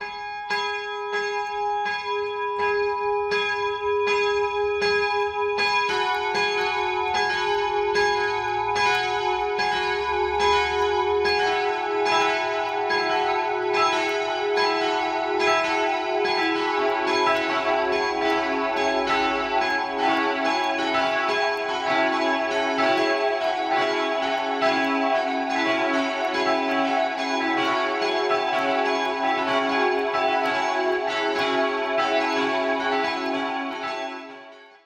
Glockengeläut – Gethsemane-Kirche
gethsemae-glocken.mp3